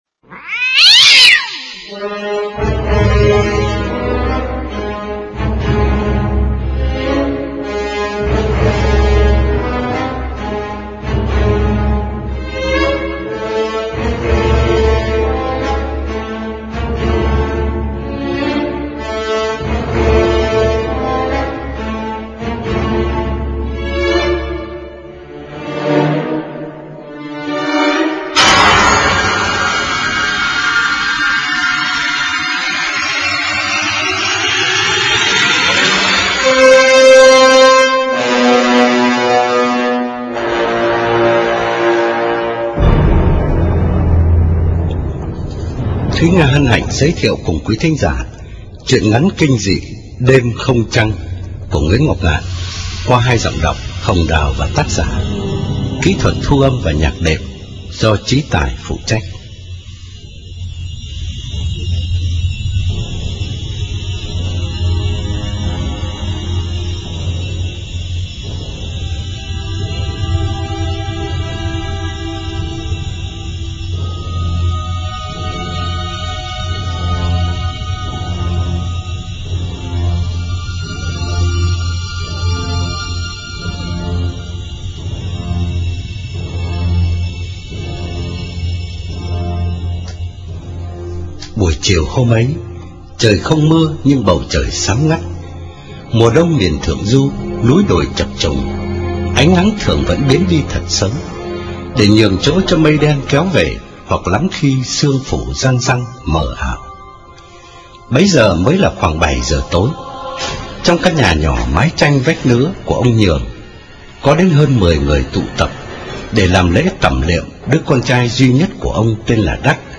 Truyện Ma Audio Đêm Không Trăng Online